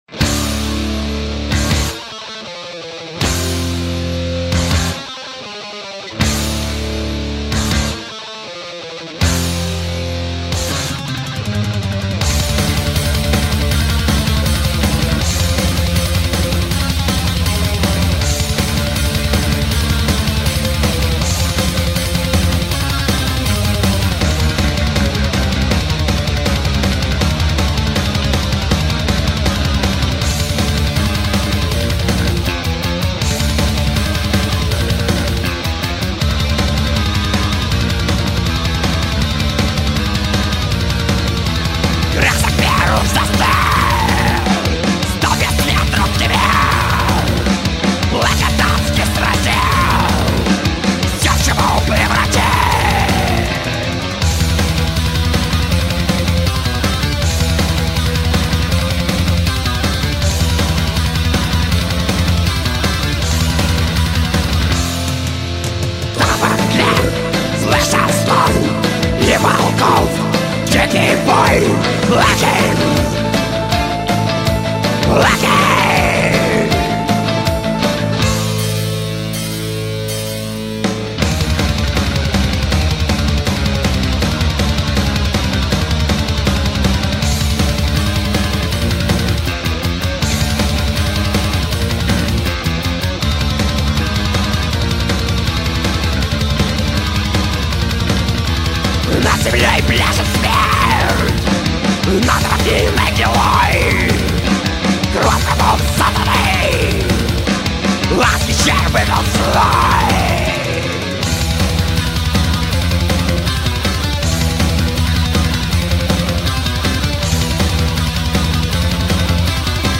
*Genre: Melodic Death Metal